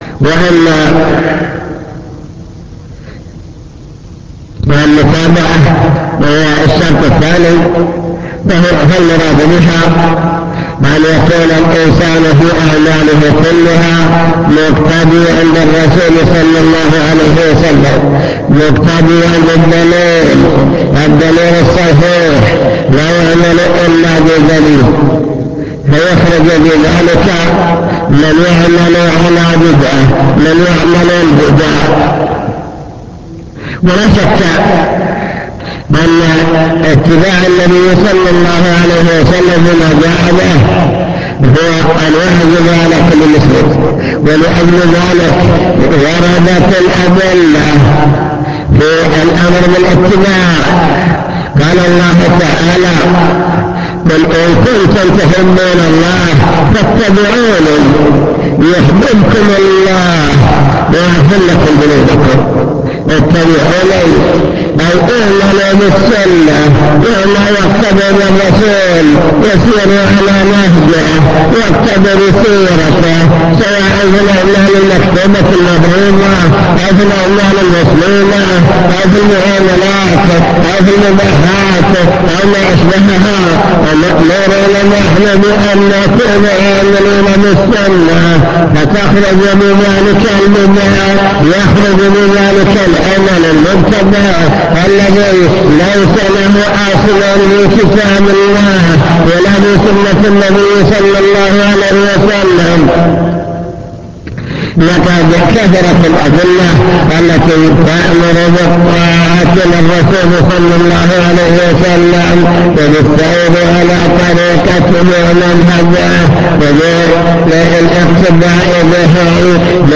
المكتبة الصوتية  تسجيلات - محاضرات ودروس  محاضرة في شروط قبول العمل الصالح بيان شروط قبول العمل الصالح عند الله تعالى